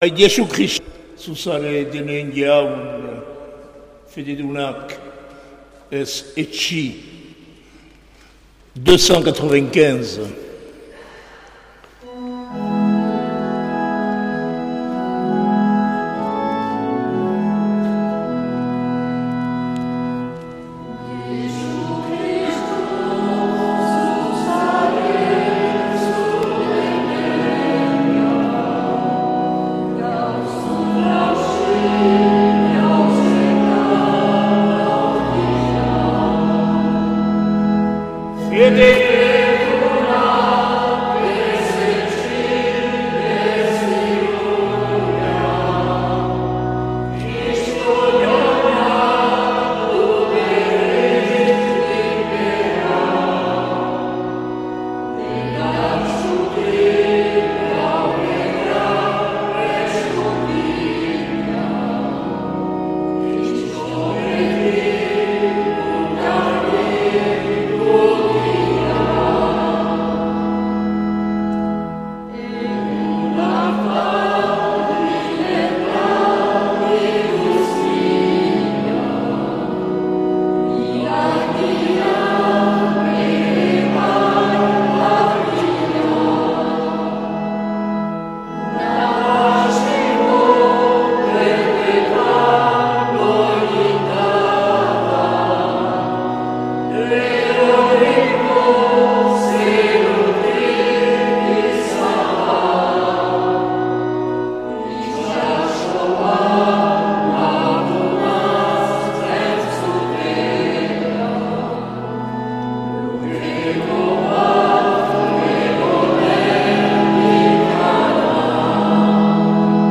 2024-11-17 Urteko 33. Igandea B - Hazparne
Accueil \ Emissions \ Vie de l’Eglise \ Célébrer \ Igandetako Mezak Euskal irratietan \ 2024-11-17 Urteko 33.